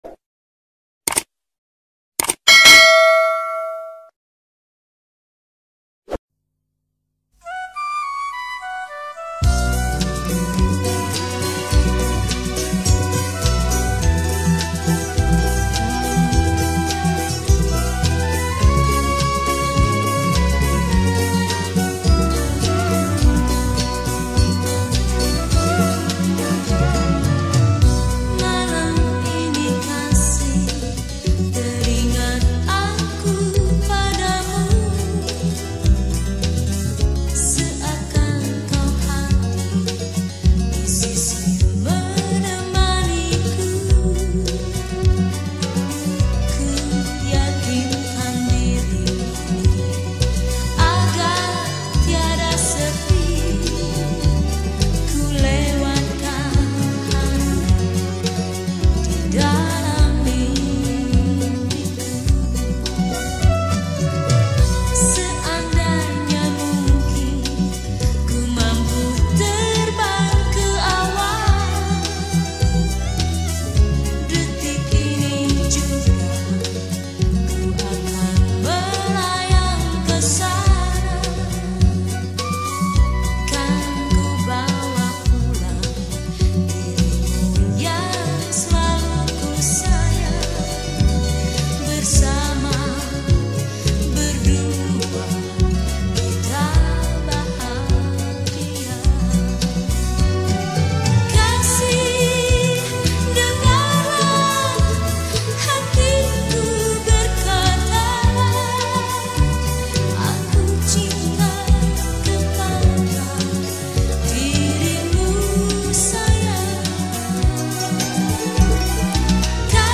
Indonesian Songs
Skor Angklung